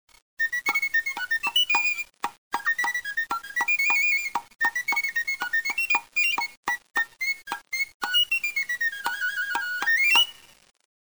Flabiol i tambori.
Le flabiol a une tessiture de deux octaves complètes.
Comparé à ses cousins, il possède un son fort et strident, rivalisant avec les instruments de la cobla puissants, et de plein air.
Cependant le son sec émis, exécuté avec une baguette en bois appelée "baqueta", porte avec décision le rythme de la sardane.
flabiol.mp3